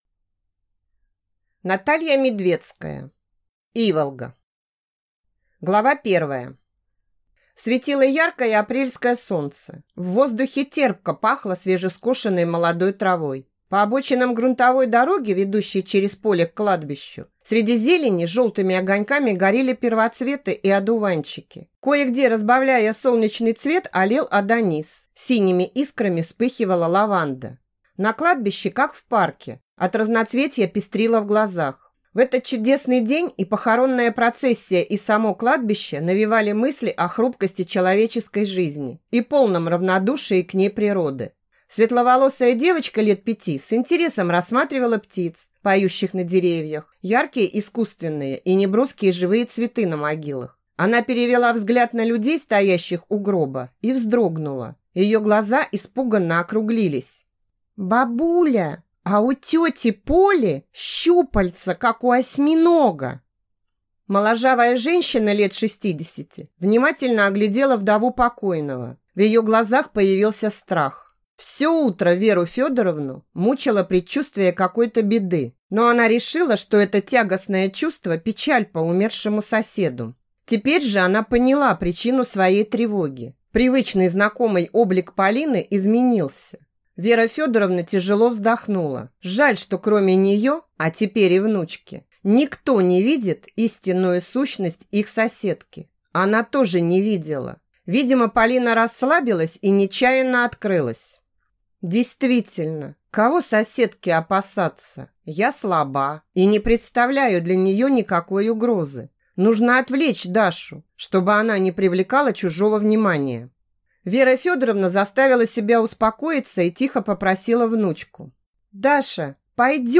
Аудиокнига Иволга | Библиотека аудиокниг